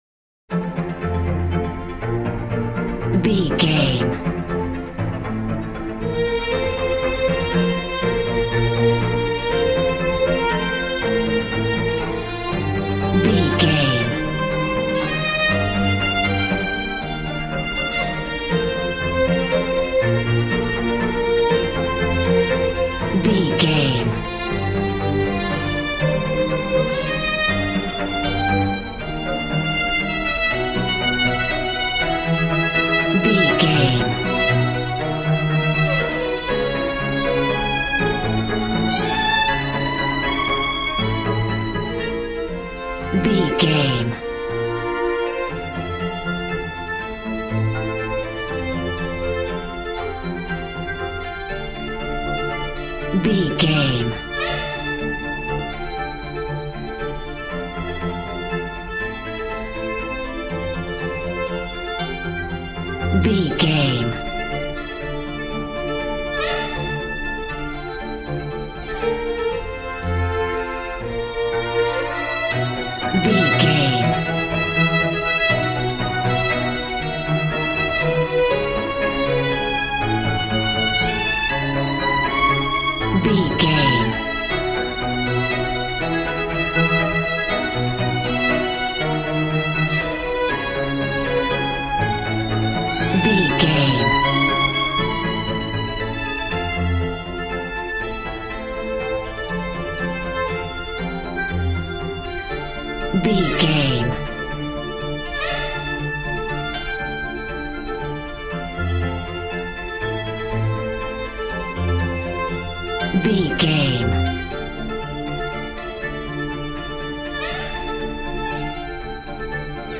Aeolian/Minor
regal
cello
violin
brass